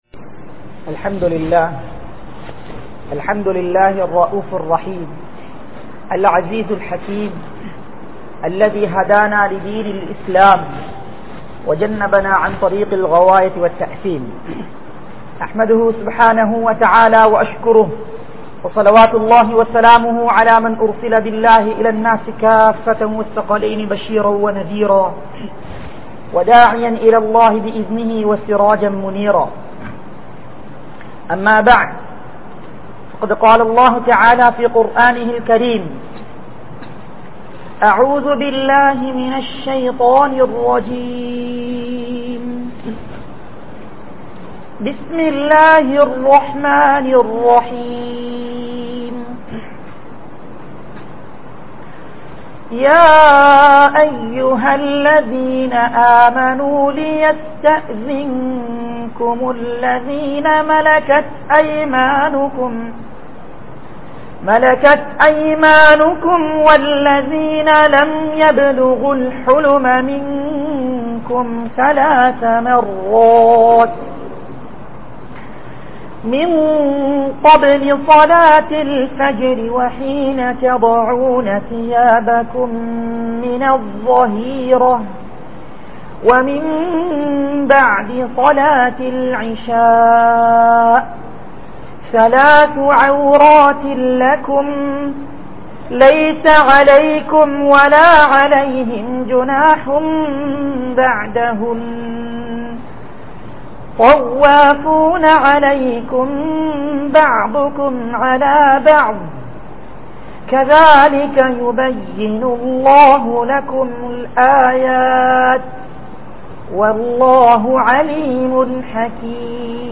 Seeralium Indraya Veettu Soolal (சீரழியும் இன்றைய வீட்டு சூழல்) | Audio Bayans | All Ceylon Muslim Youth Community | Addalaichenai